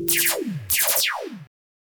Звуки пиу
Звук лазерного выстрела пиу пиу